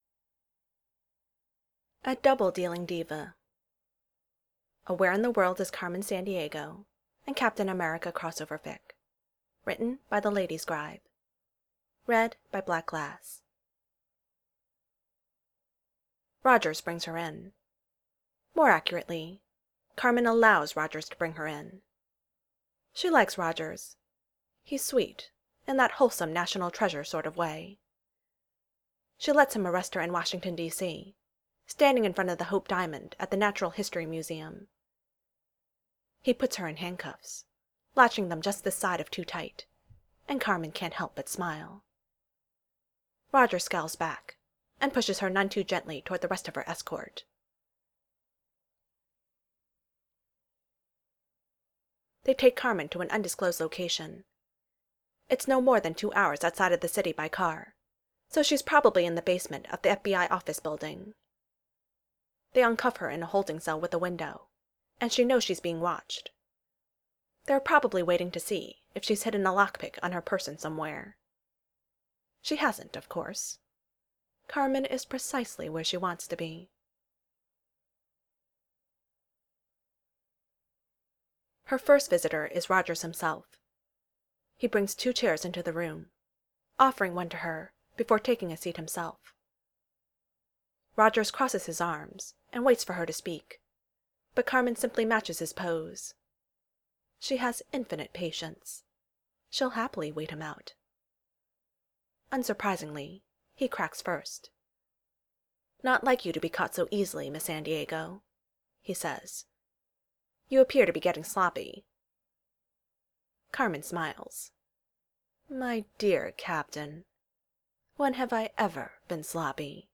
a double-dealing diva (no music).mp3